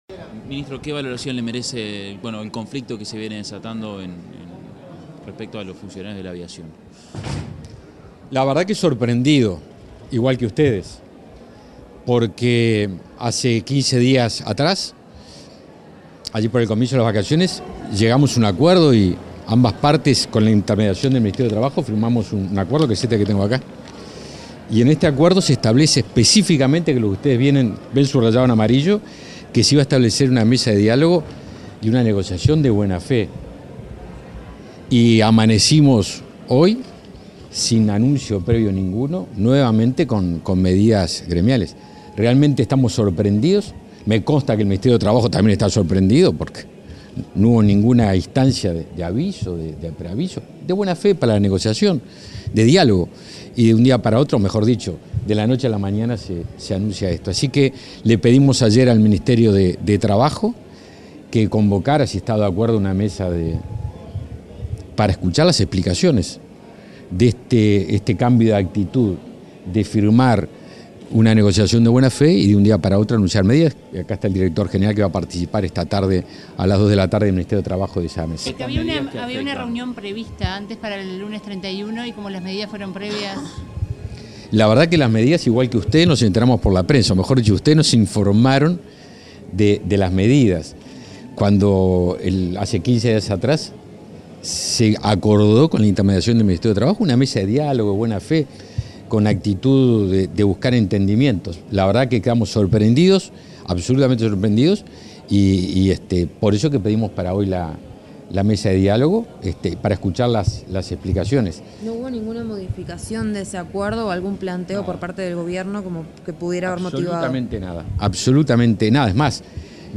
Declaraciones del ministro de Defensa Nacional, Javier García
El ministro de Defensa Nacional, Javier García, dialogó con la prensa luego de participar en el 115.° aniversario del Hospital Central de las Fuerzas